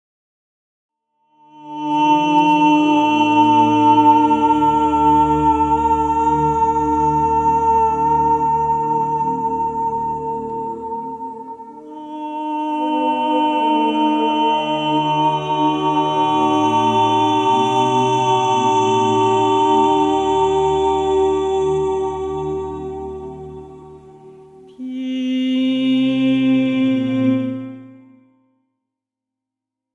Meditative
Momentum-Aufnahmen